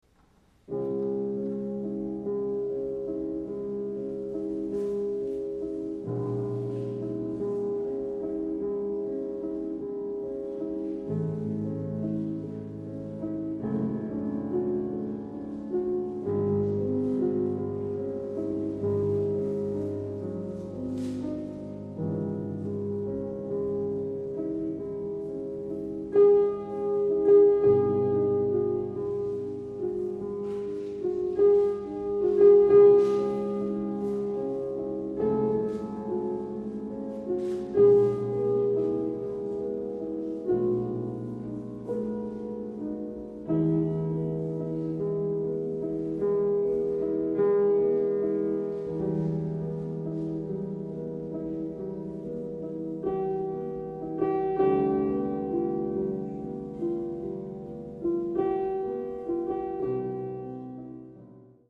Sonate cis-Moll op. 27
Adagio sostenuto